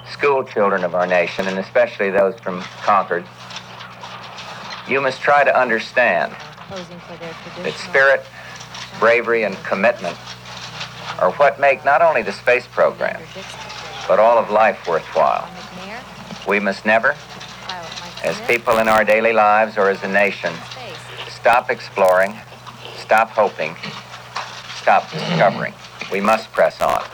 George Bush speaks to schoolchildren who watched the Challenger space shuttle disaster, saying that the tragedy will not deter the space program